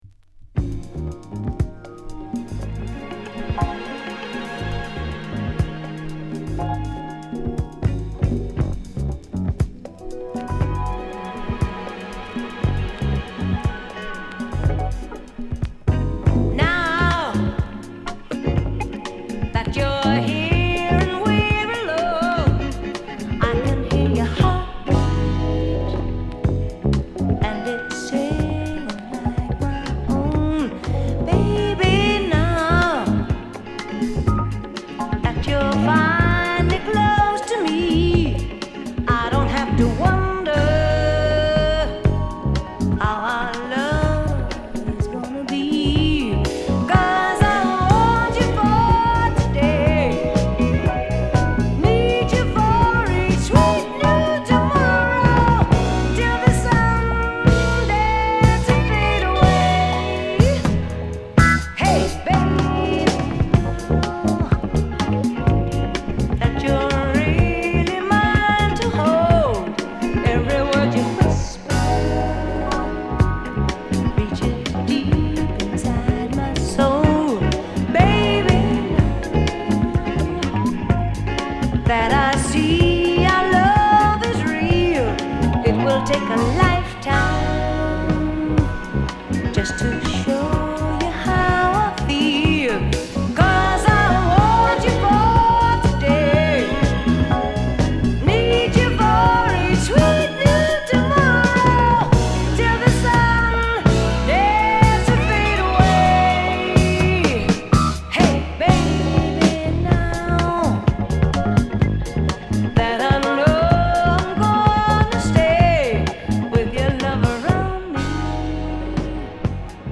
グルーヴィンなアレンジ効いた爽快ダンサーに！伸びやかなヴォーカルも間違い無しです。